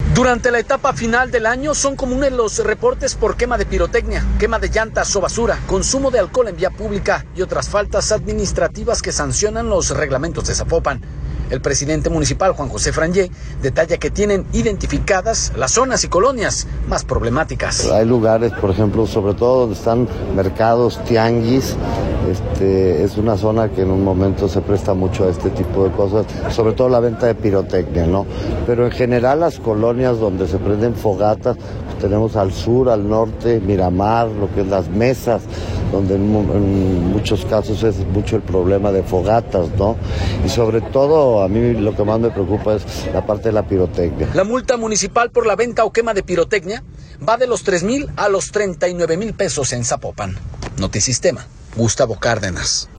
Durante la etapa final del año son comunes los reportes por la quema de pirotecnia, quema de llantas o basura, consumo de alcohol en la vía pública y otras faltas administrativas que sancionan los reglamentos de Zapopan. El presidente municipal, Juan José Frangie, detalla que tienen identificadas las zonas y colonias más problemáticas.